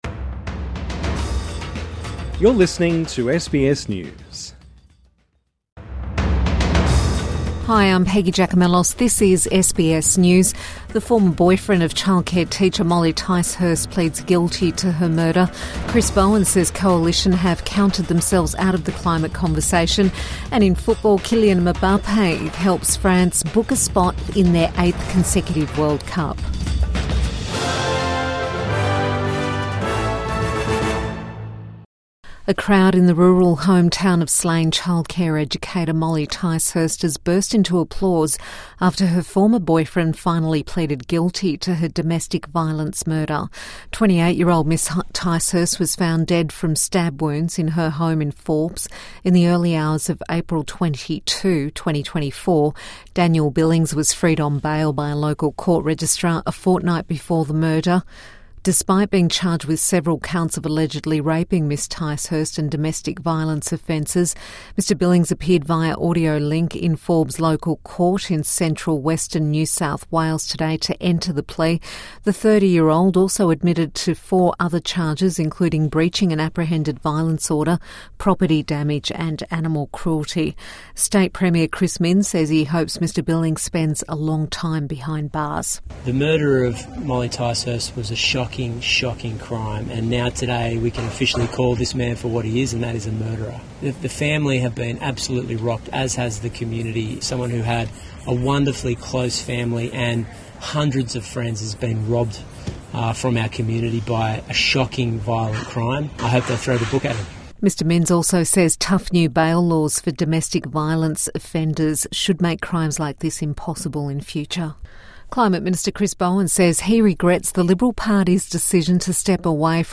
Former boyfriend of childcare teacher pleads guilty to her murder | Evening News Bulletin 14 November 2024